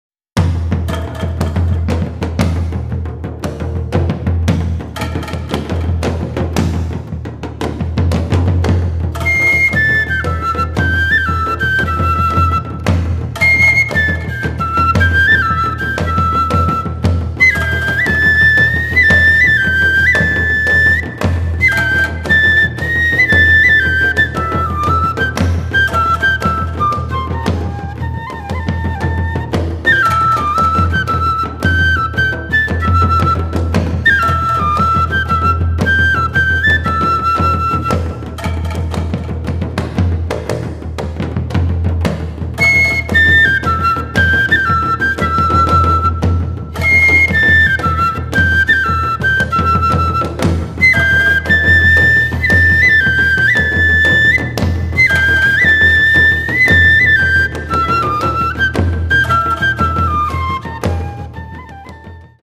at studio Voice
横笛(陶器)、アンクロン